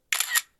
cameraShutter.mp3